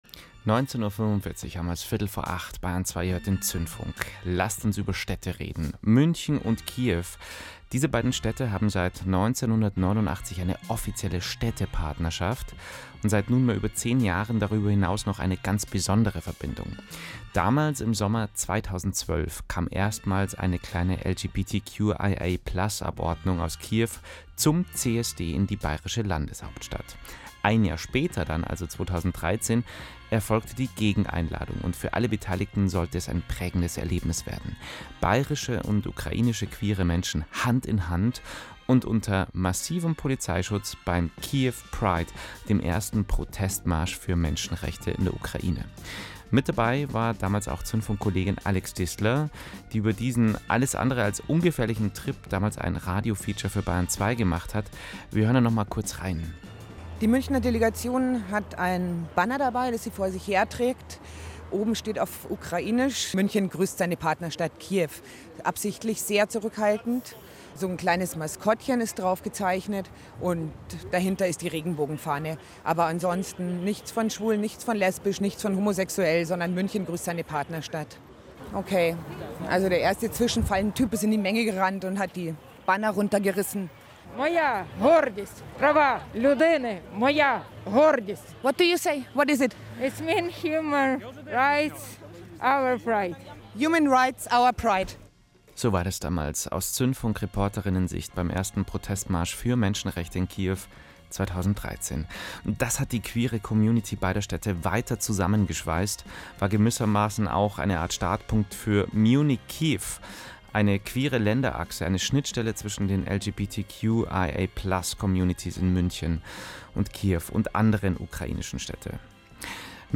Mitschnitt-Interview-Queere-Ukrainer-Innen.mp3